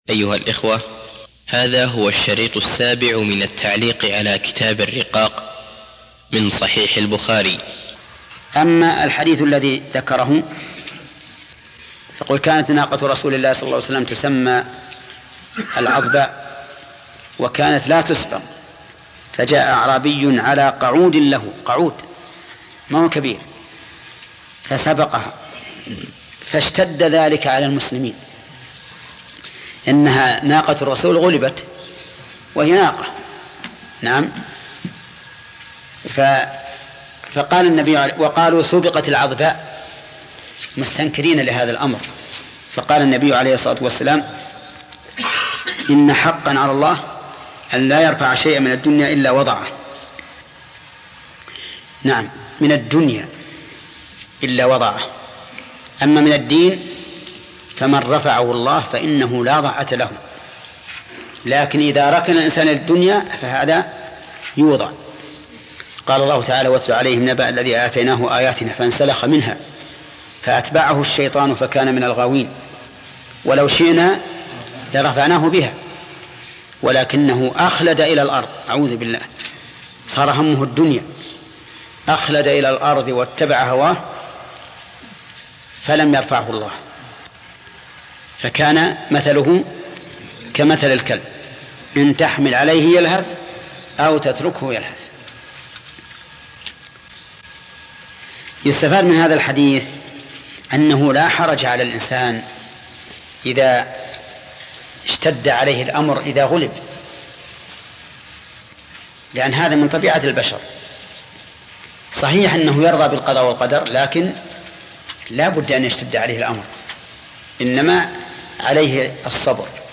الدرس السابع